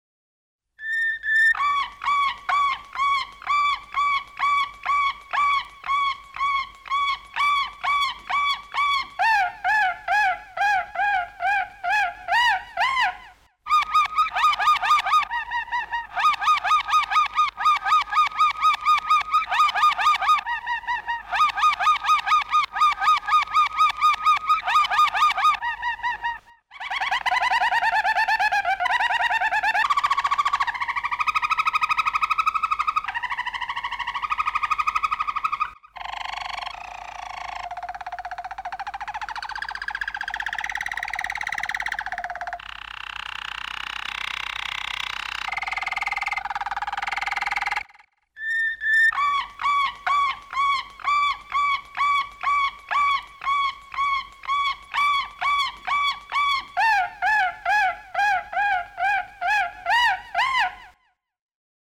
Suara Burung Kenari Roller Jerman
1. Suara Kenari Roller Jerman 1